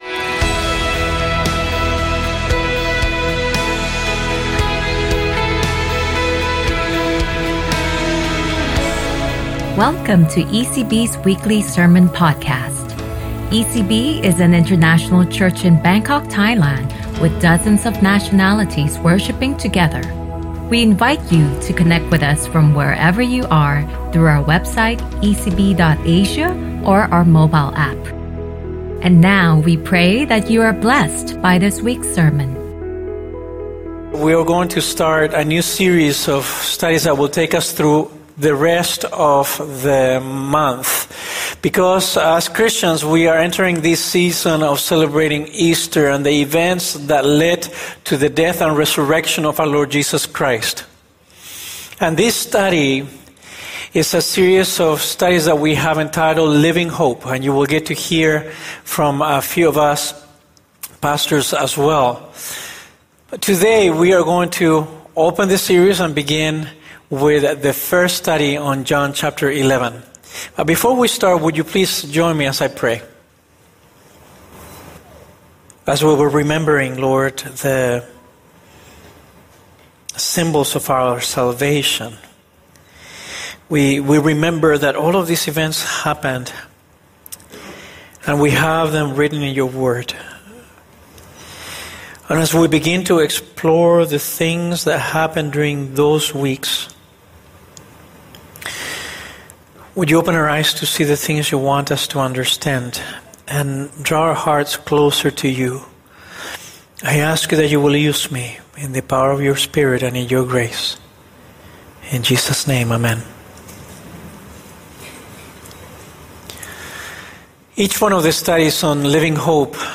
ECB Sermon Podcast